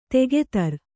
♪ tegetar